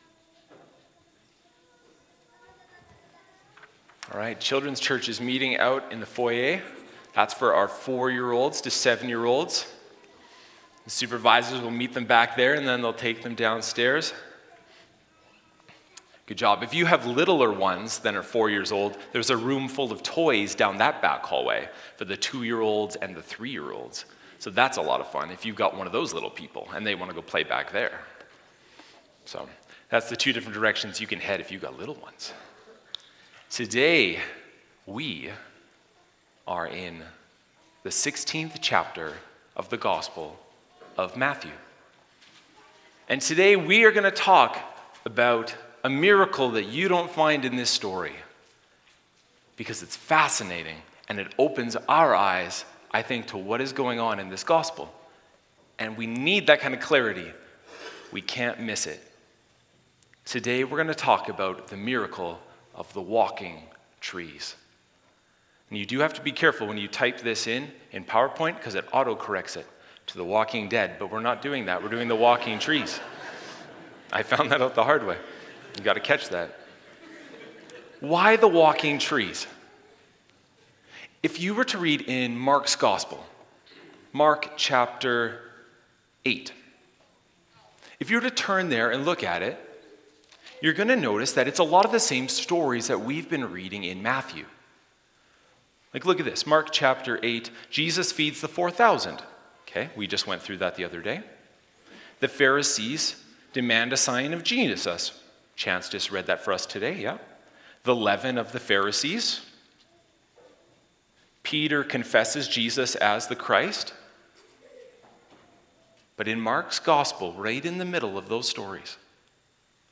Sermons | Bridgeway Community Church